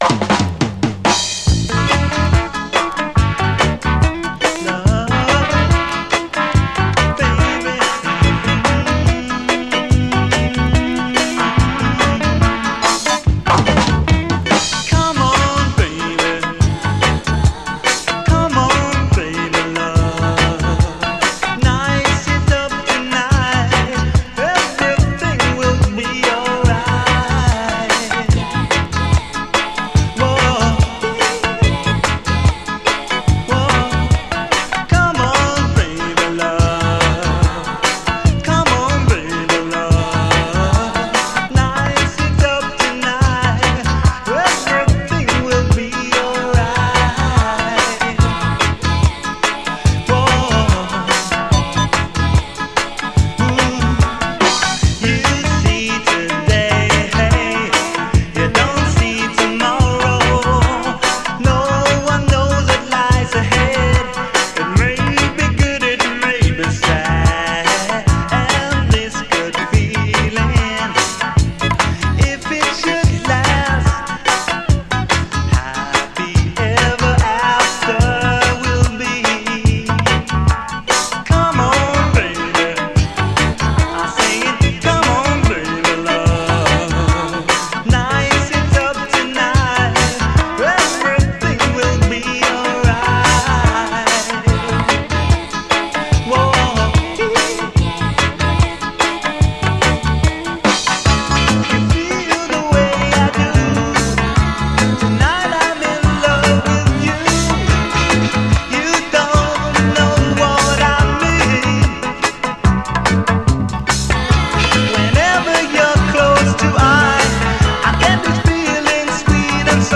UK産最高ステッパー・ラヴァーズ！可愛らしい女性コーラスと切なくメロディアスな展開が非常にUKラヴァーズ的！